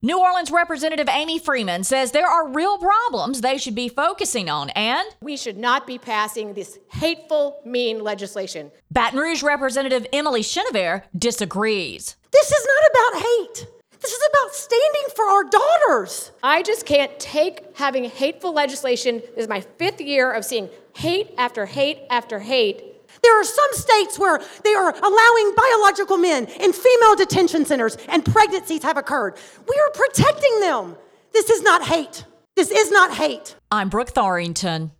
bathroom-bill-house-debate.wav